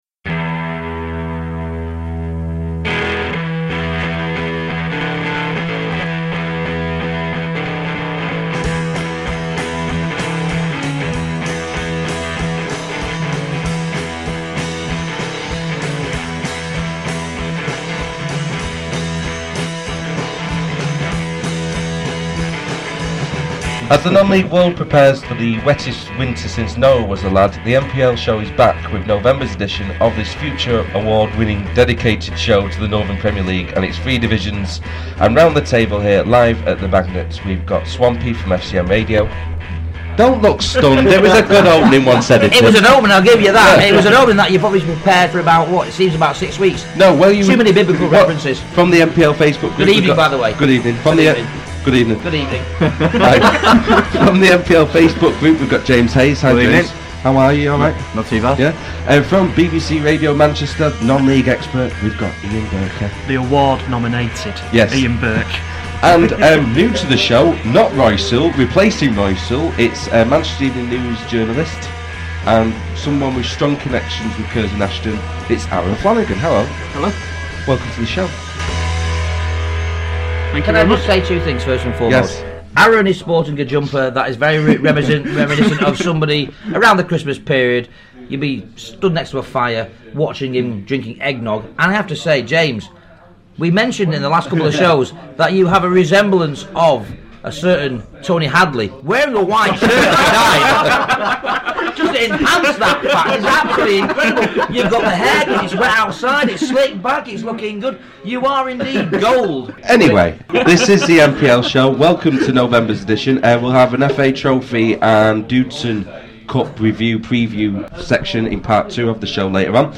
With Thanks: This programme was recorded at the Magnet in Stockport on Wednesday 6th November 2013.